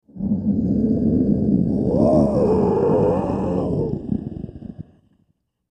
Alien Breathing; Large Creature Growl And Wheeze Breaths, Close Pov.